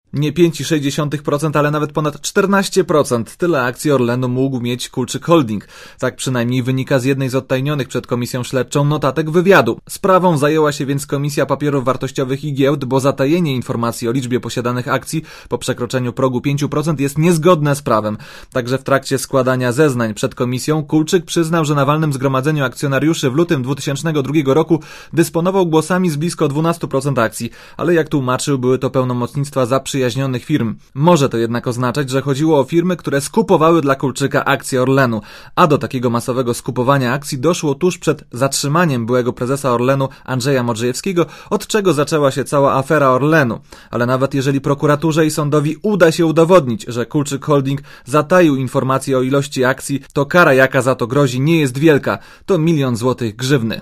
Relacja
Być może niezależnie od wniosku Komisji Papierów Wartościowych i Giełd także sejmowa komisja śledcza złoży własne zawiadomienie o możliwości popełnienia przestępstwa - komentuje członek sejmowej komisji śledczej do spraw PKN Orlen Antoni Macierewicz.